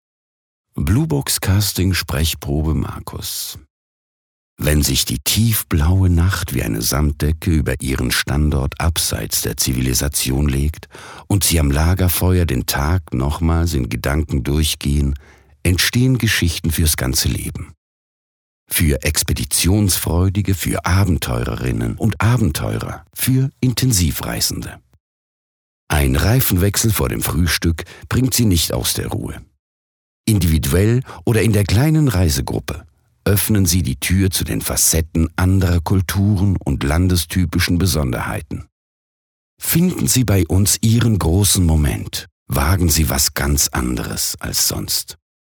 OFF Hochdeutsch (CH) Hörprobe 01